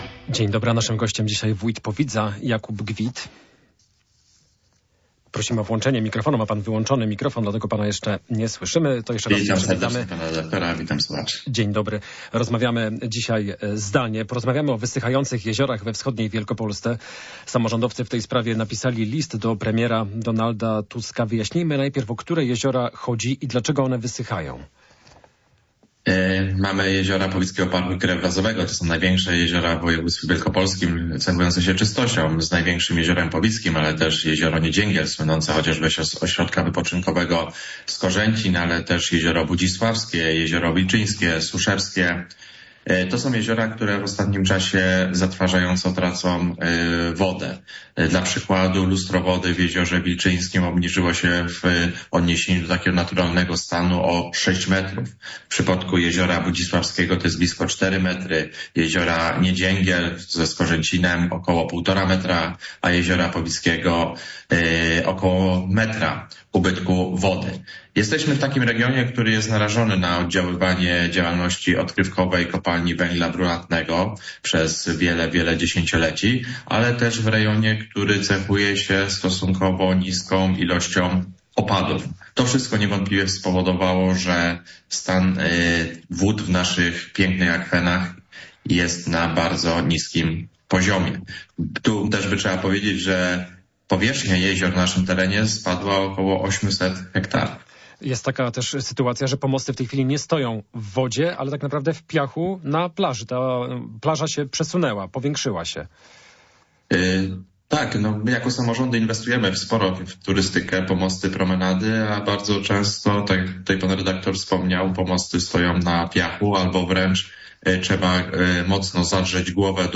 Jak uratować wysychające jeziora we wschodniej Wielkopolsce? Gościem Radia Poznań jest wójt Powidza Jakub Gwit.